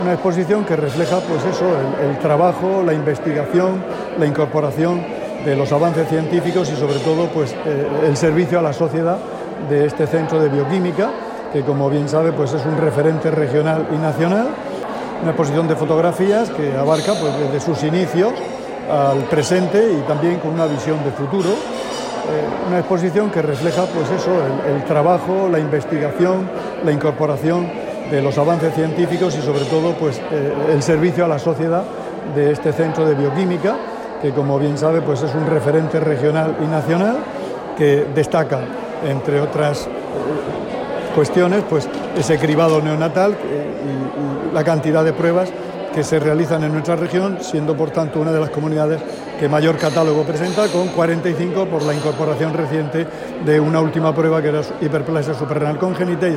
Sonido/ Declaraciones del consejero de Salud, Juan José Pedreño, sobre la exposición de fotografías del Centro de Bioquímica y Genética Clínica.